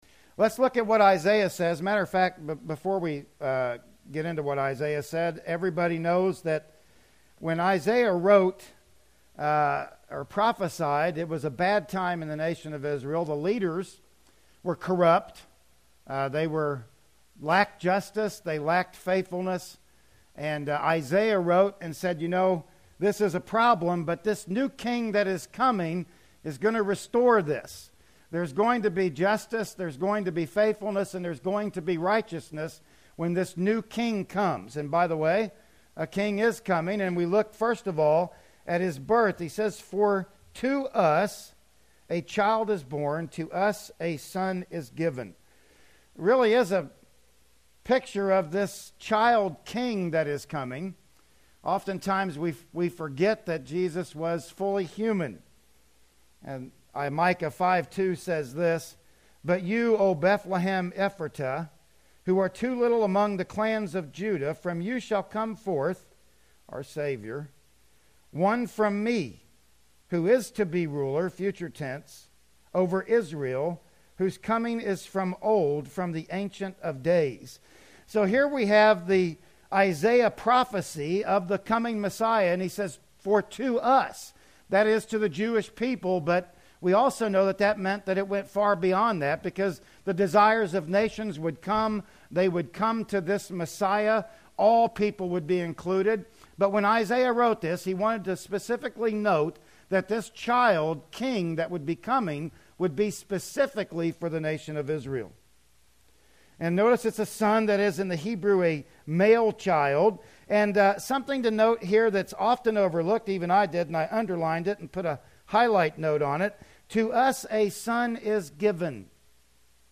Passage: "Isaiah 9:6" Service Type: Sunday Morning Worship Service